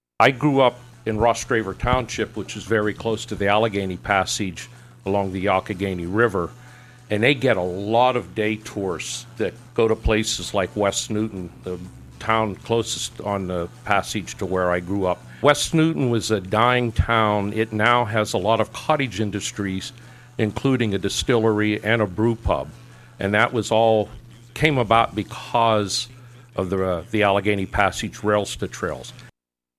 This morning on WCCS AM 1160 and 101.1 FM, two debates were held concerning two positions of county government that are on the ballot for next week’s election.